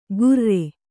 ♪ gurre